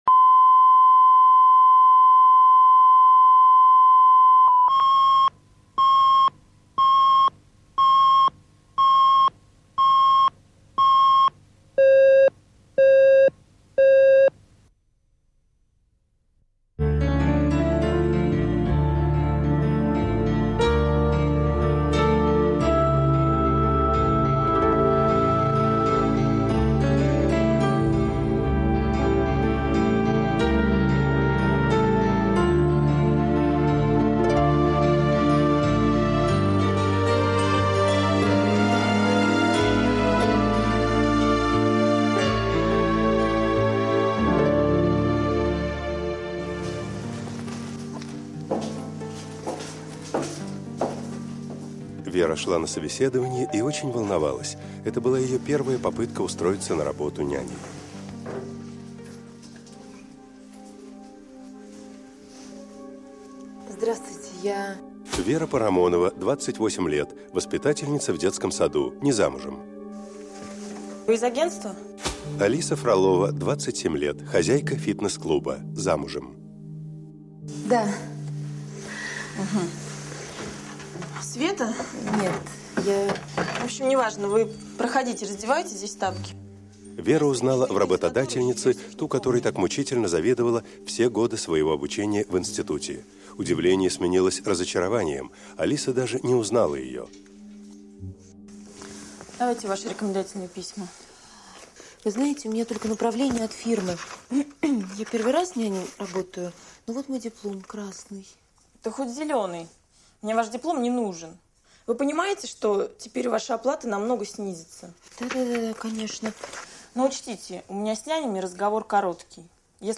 Аудиокнига С чужого плеча